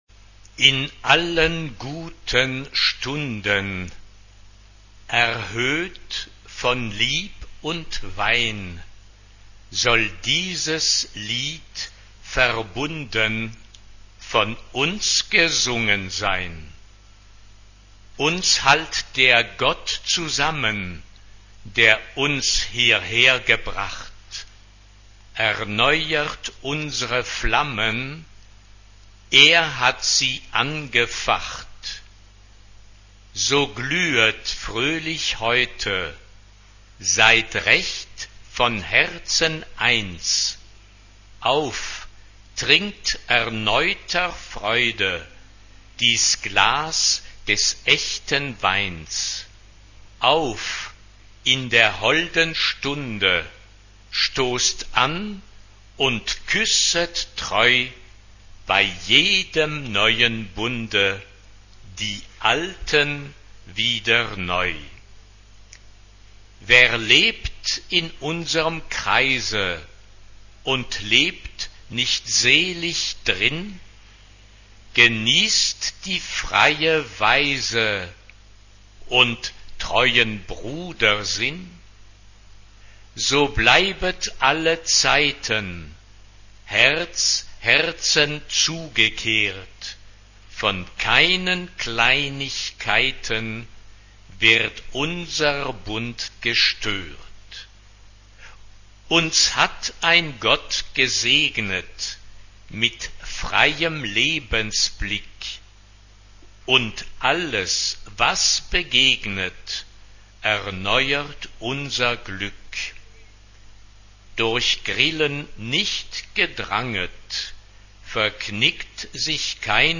SA (2 Frauenchor Stimmen) ; Mietmaterial.
Chorwerk. weltlich.
Solisten: Soprane (2) (2 Solist(en)) Instrumentation: Bläsergruppe (6 Instrumentalstimme(n)) Instrumente: Klarinette (2) ; Fagott (2) ; Horn (2) Tonart(en): B-dur